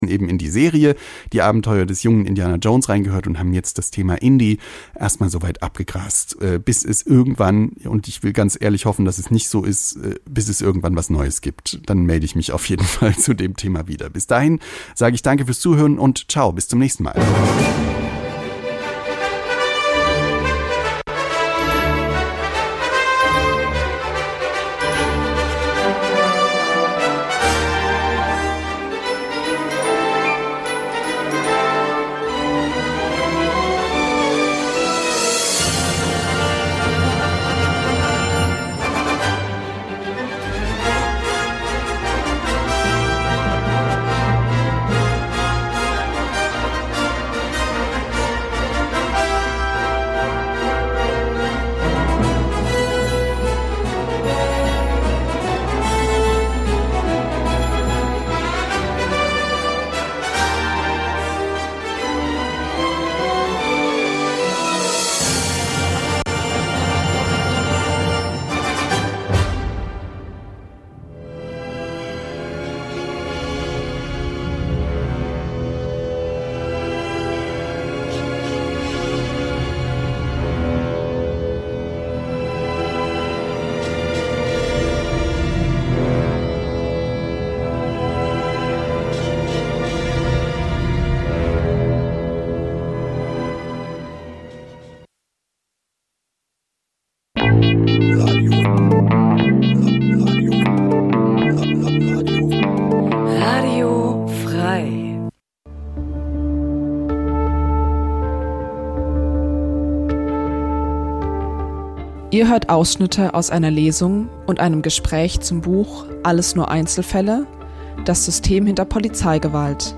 Alles nur Einzelf�lle? Das System hinter Polizeigewalt Lesung und Gespr�ch mit Mohamed Amjahid | 24. Juni 2025 im Caf� Nilo's